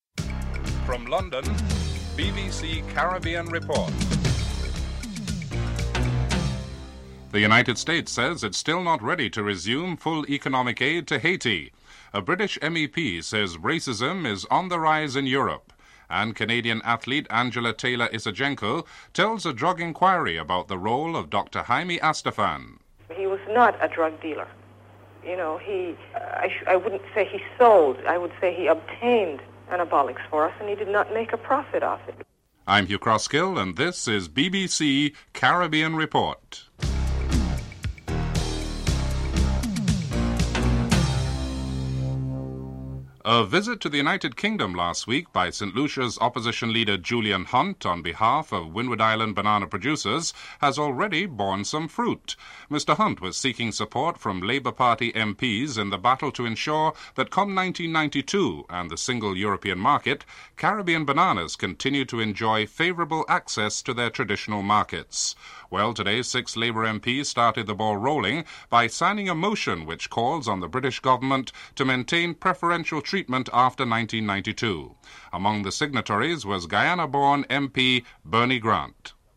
1. Headlines (00:00-00:42)
2. Interview with British Labor MP, Bernie Grant on the signing of a motion which calls for preferential treatment to banana producers in the Windward Islands (00:43-03:33)
3. Interview with Glyn Ford, British Member of the European Parliament on the need to re-visit immigrants rights and the rise in racism in Europe (03:34-06:16)
4. Financial News (06:17-07:28)